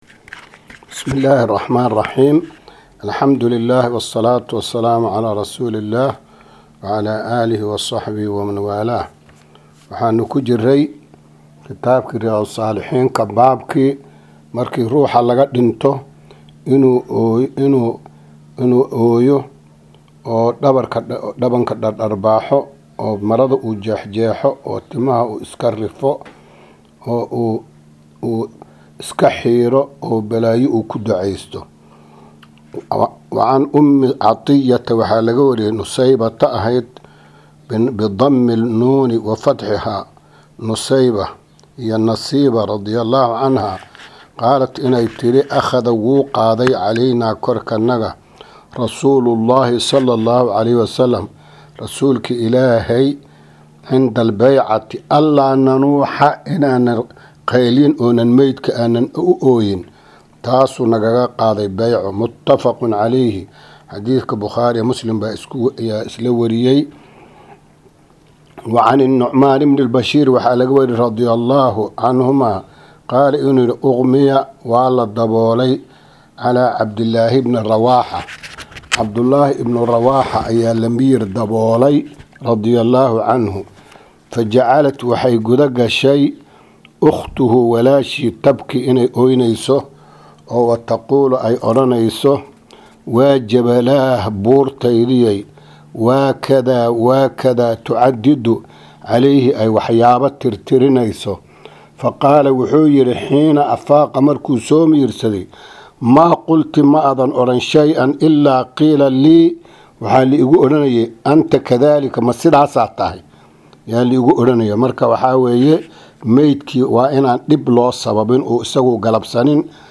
Riyaadu Saalixiin Casharka 89aad